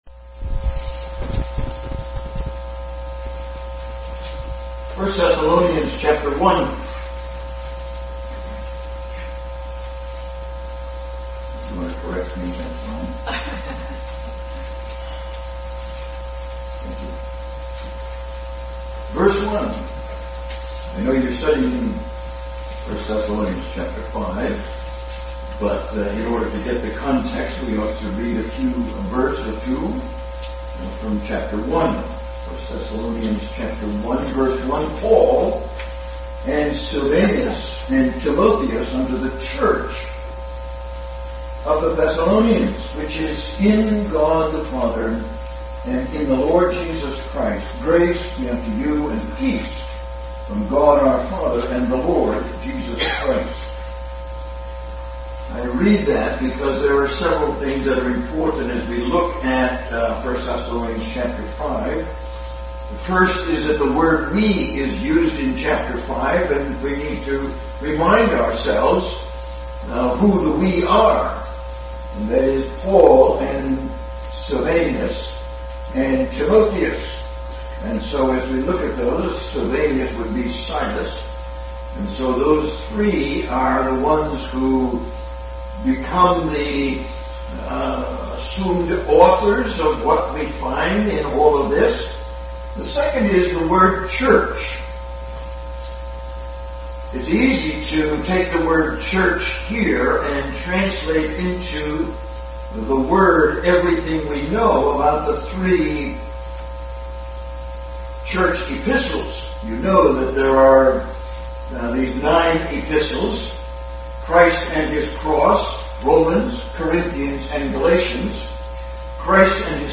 Bible Text: 1 Thessalonians 5:12-15, 1:1-4 | Adult Sunday School Class continued study in the book of Thessalonians.